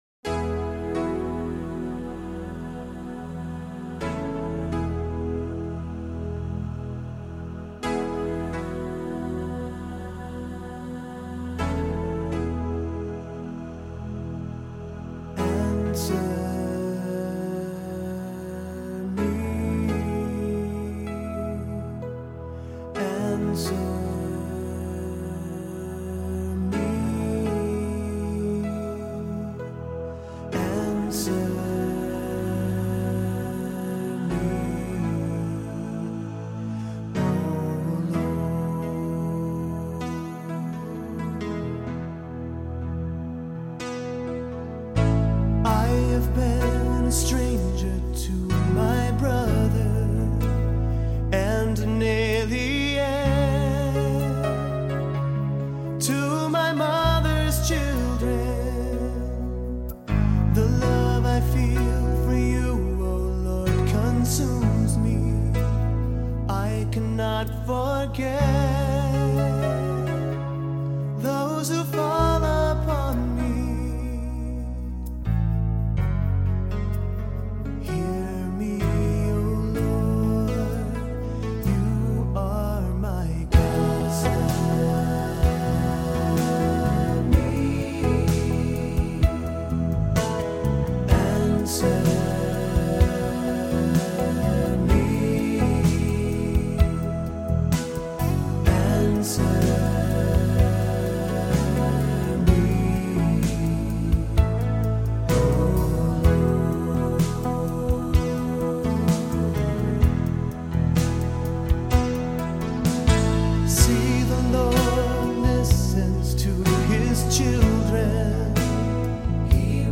Voicing: Assembly,SATB